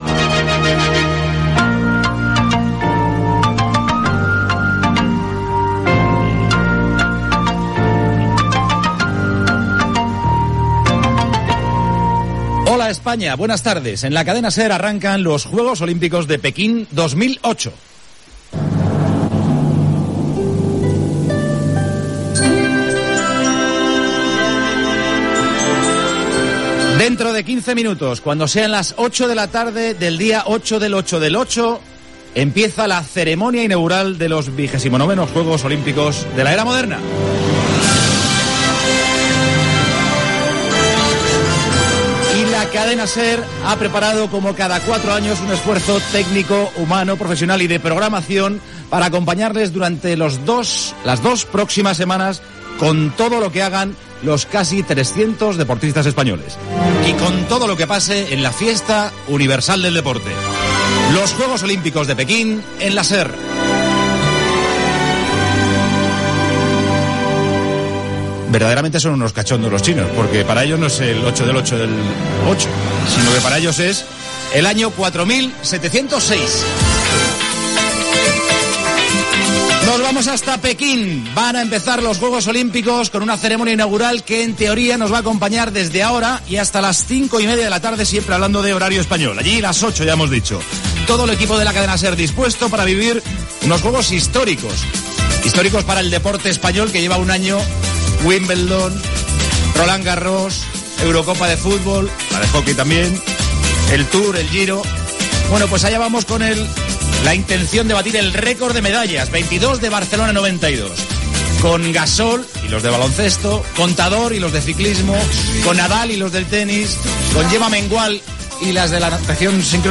0c1119e5f517de470e9a220a9e5b4cdd37c4cc89.mp3 Títol Cadena SER Emissora Ràdio Barcelona Cadena SER Titularitat Privada estatal Nom programa Pekín 2008 Descripció Sintonia de la cadena, salutació, presentació de la programació especial de la Cadena SER per seguir els Jocs Olímpics de Pequín 2008. Repàs a alguns dels esportistes més destacats que hi participaran. Connexió amb l'Estadi del Niu de Pequín per narrar la cerimònia inaugural.
Gènere radiofònic Esportiu